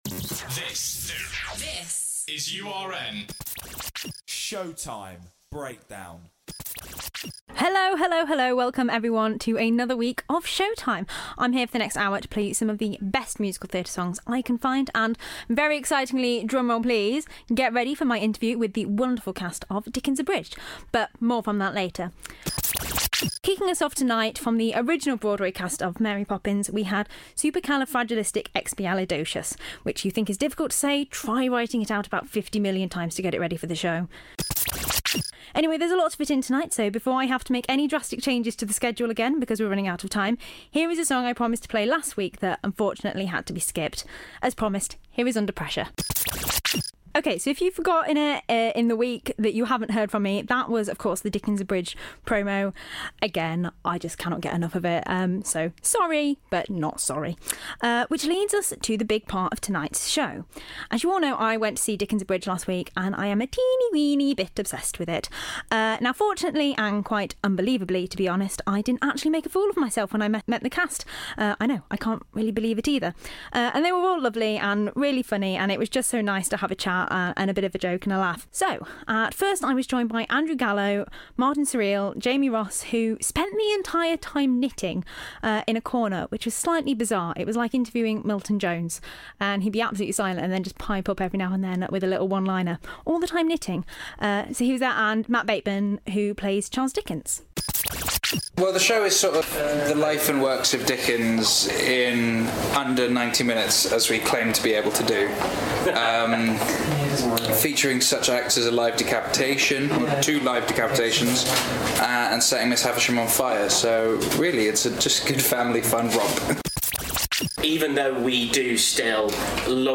In this week's episode of your favourite musical theatre show, I interview the wonderful cast of Dickens Abridged and play some of my favourite show tunes (as always).